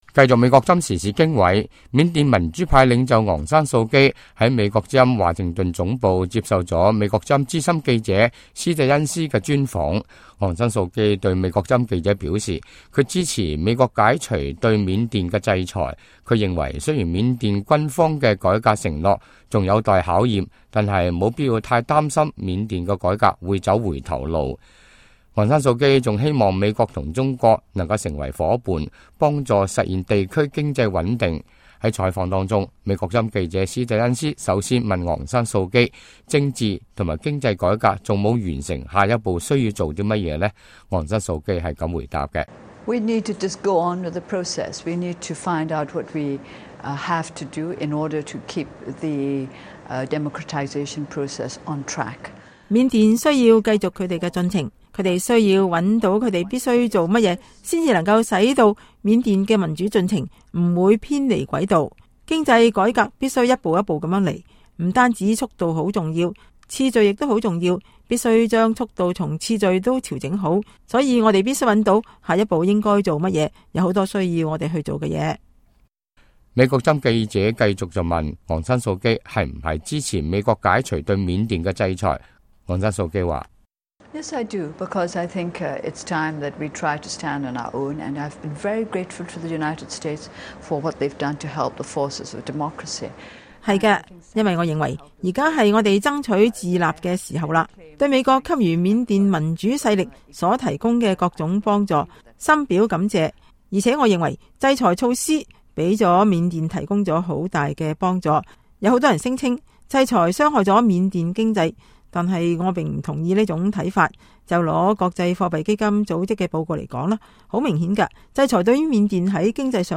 美國之音專訪昂山素姬